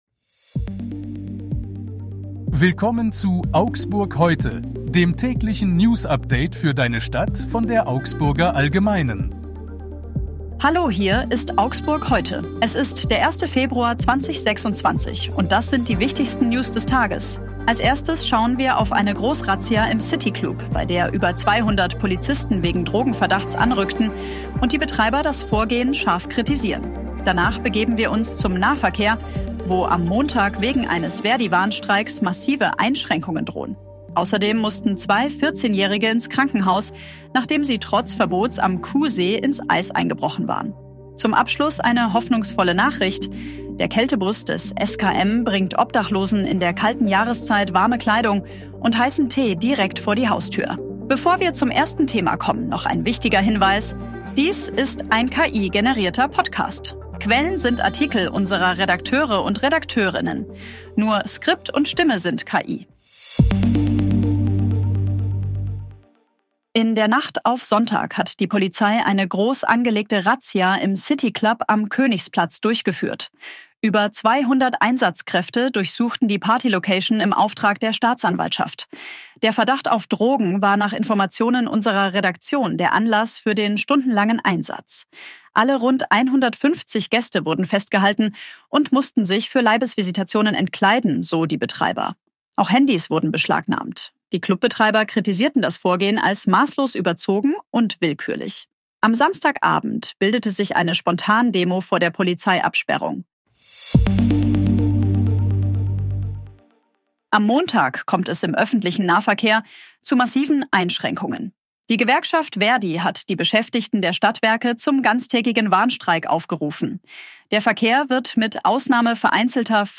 Stimme sind KI.